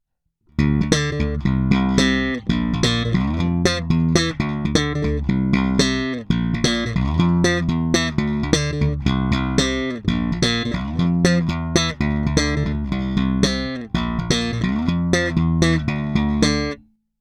Zvukově je to naprosto klasický a opravdu skvělý Jazz Bass.
Není-li uvedeno jinak, následující nahrávky jsou provedeny rovnou do zvukové karty a bez stažené tónové clony.